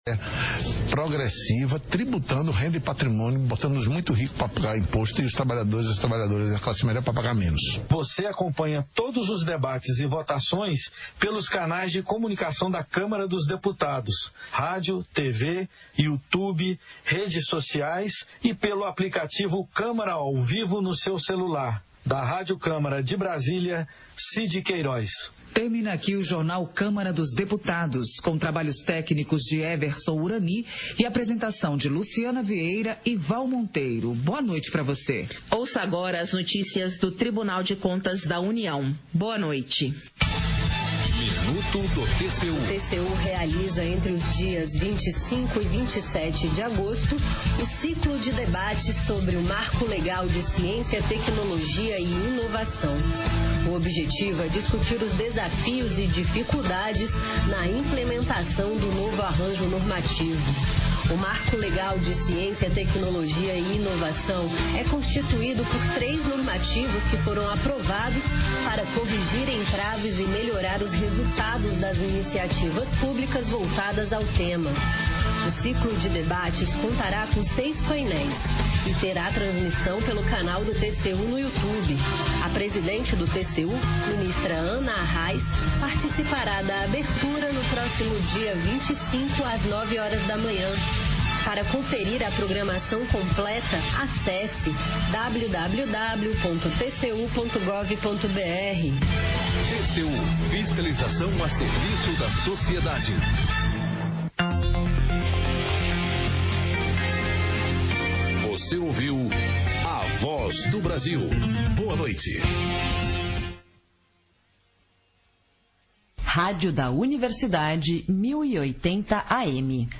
audios sessão — Câmara Municipal de Vereadores